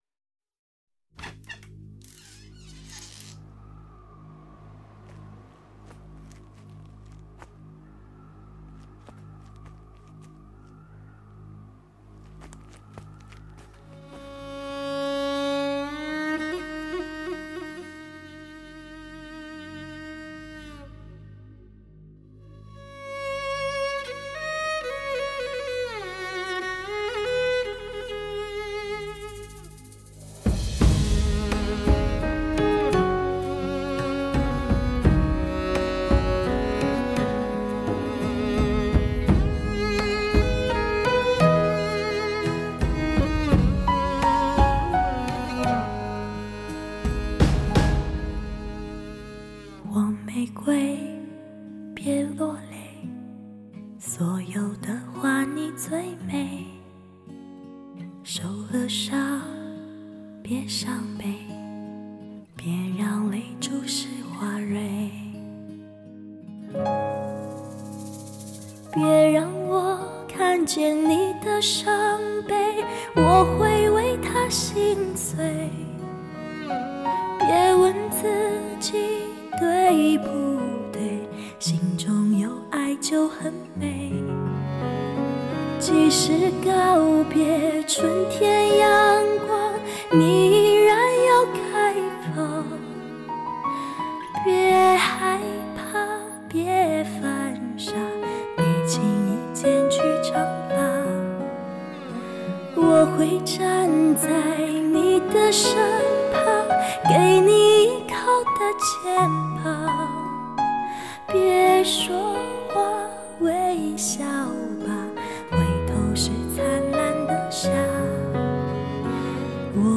清纯，通透，充满磁性，天使般空灵的真空管女声。
真空管录音极品，将声音带到另一个境界，如亲临音乐厅欣赏音乐会一般。